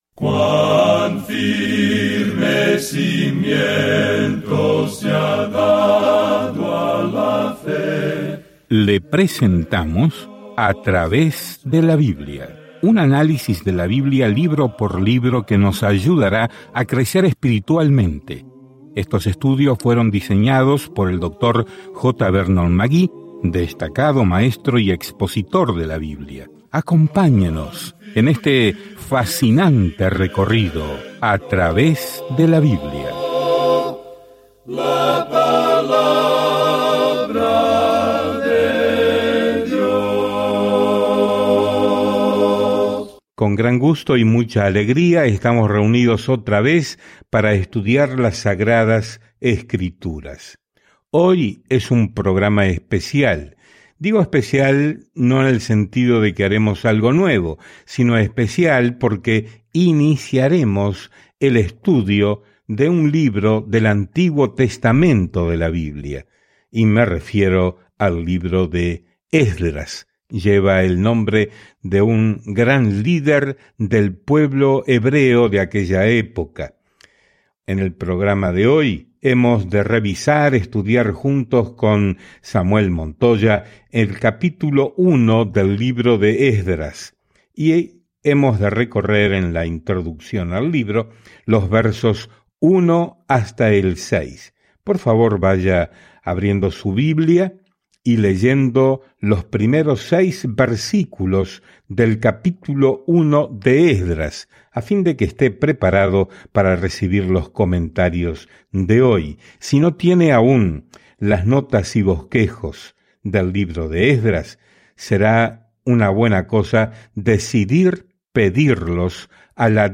Escrituras ESDRAS 1:1-6 Comenzar este Plan Día 2 Acerca de este Plan El pueblo de Israel, que regresa del cautiverio, reconstruye el templo en Jerusalén, y un escriba llamado Esdras les enseña cómo obedecer una vez más las leyes de Dios. Viaja diariamente a través de Ezra mientras escuchas el estudio de audio y lees versículos seleccionados de la palabra de Dios.